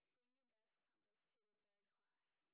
sp27_train_snr30.wav